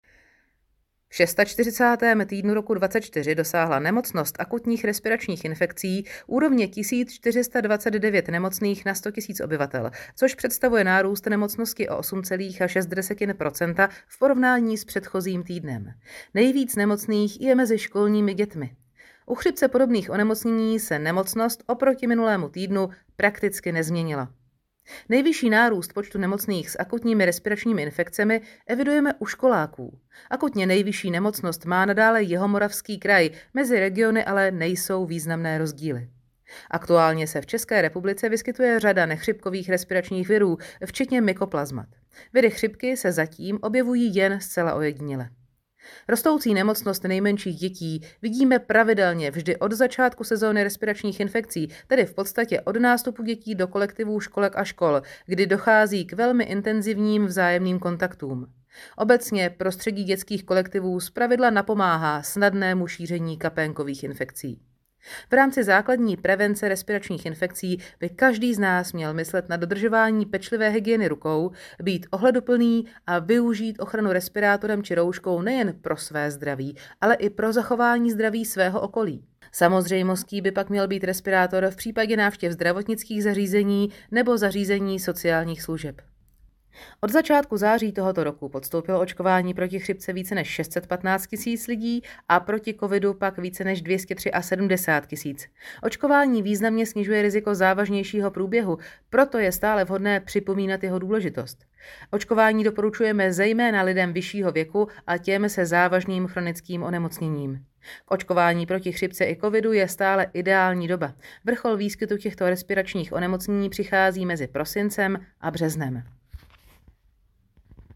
Ke stažení - podrobná zpráva o výskytu akutních respiračních infekcí a verze tiskové zprávy ve zvuku